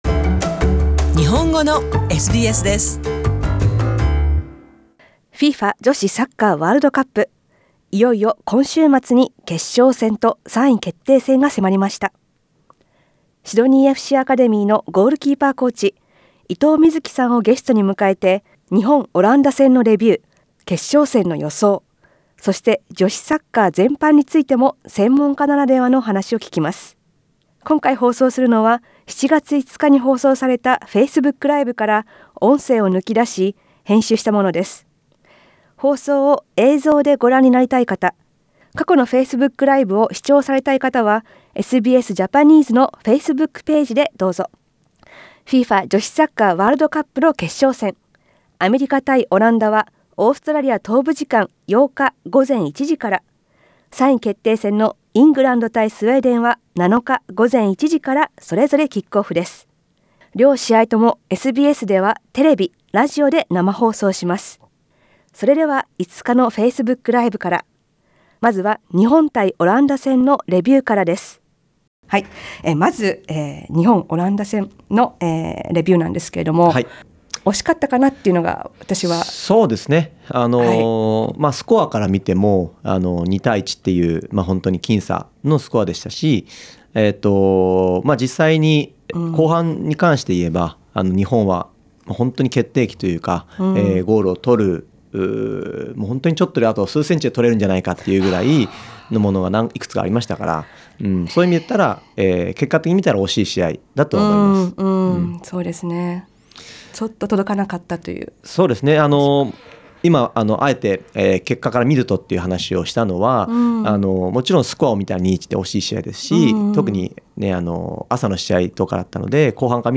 SKIP ADVERTISEMENT このインタビューは、日本語ラジオのフェイスブックで7月5日にライブ配信された内容を編集したものです。今回は日本対オランダの一戦を振り返りつつ決勝戦の展開を予想、そして総括として、女子サッカー全般についての話も聞いています。